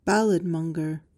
PRONUNCIATION:
(BAL-uhd-mong-guhr)